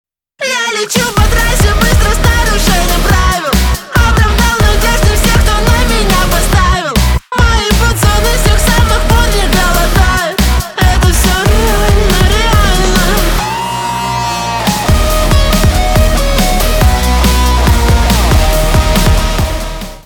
альтернатива
гитара , барабаны , качающие